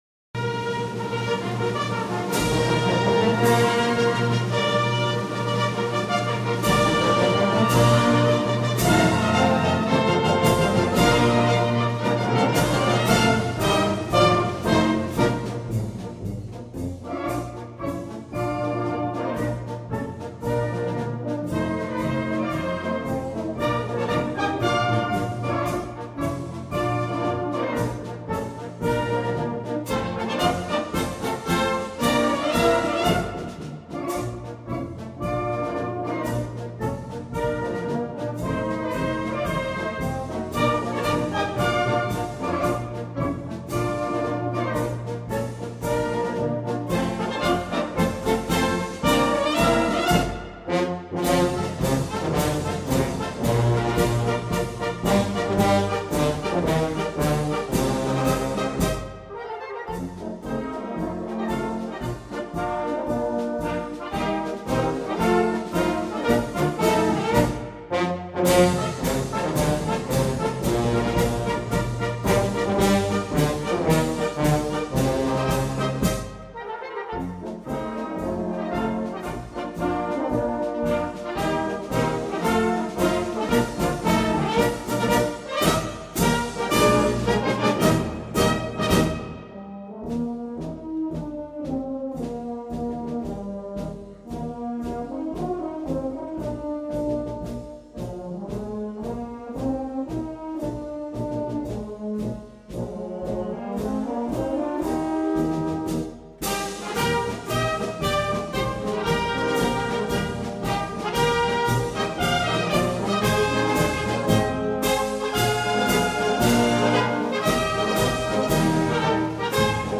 Gattung: Konzertmarsch für Blasorchester